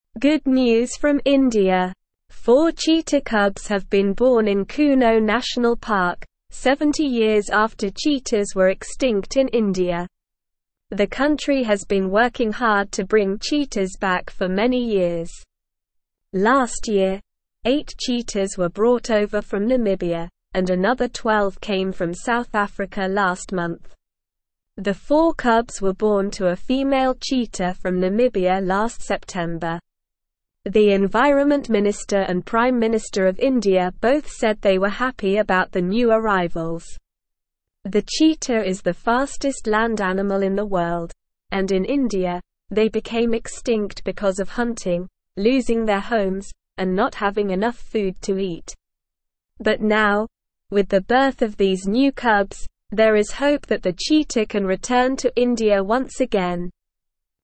Slow
English-Newsroom-Beginner-SLOW-Reading-India-Has-Four-New-Baby-Cheetahs.mp3